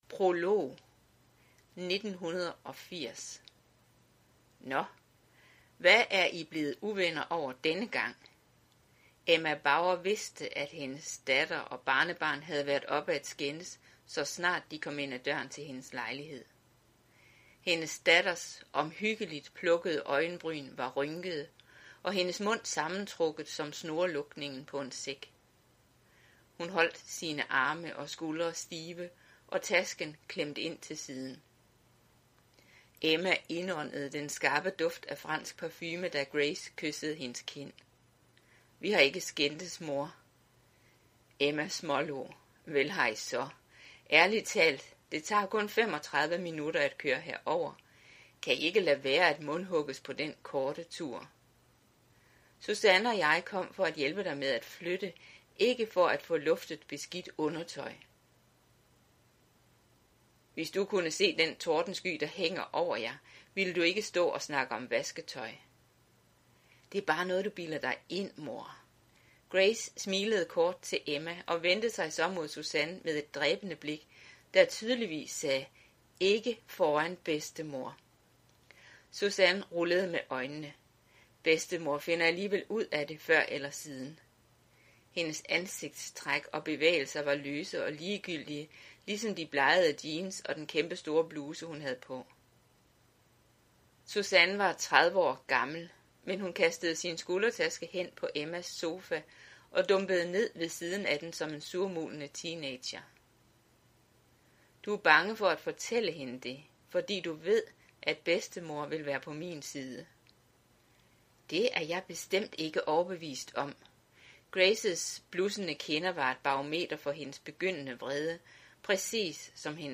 Hør et uddrag af Evas døtre Evas døtre Format MP3 Forfatter Lynn Austin Bog Lydbog E-bog 99,95 kr.